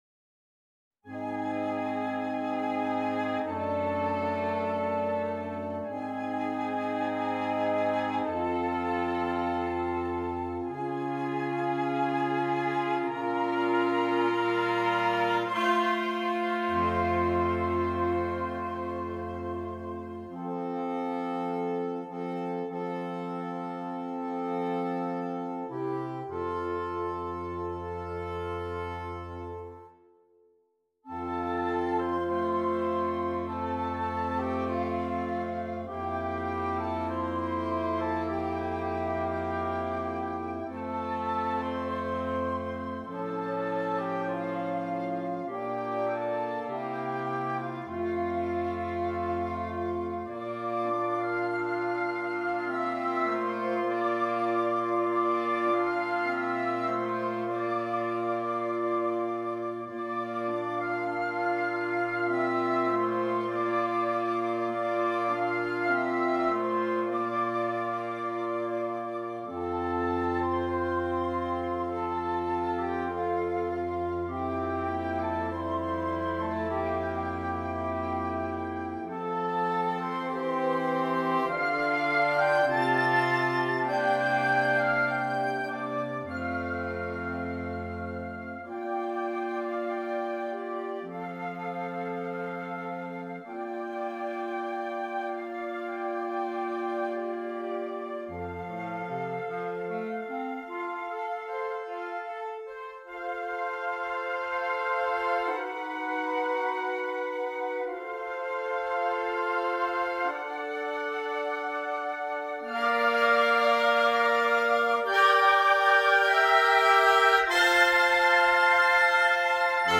Voicing: Flexible Woodwind Quintet